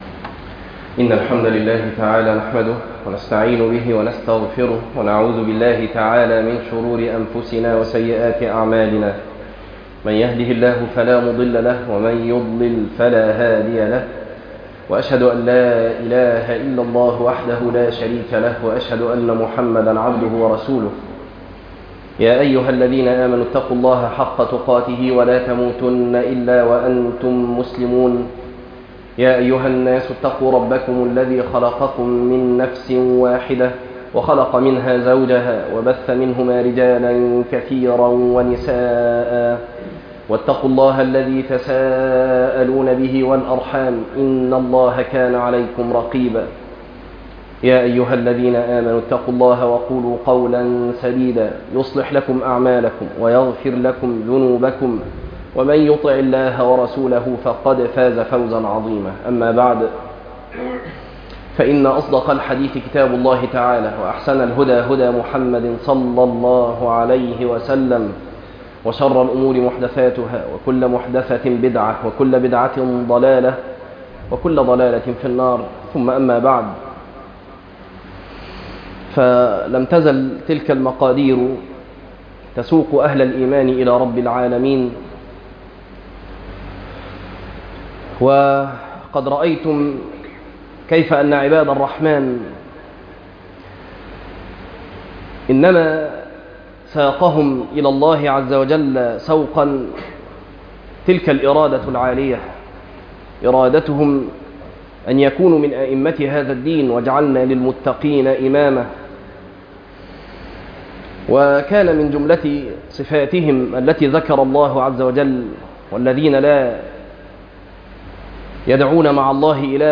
٤- وعباد ٱلرحمـن - خطبة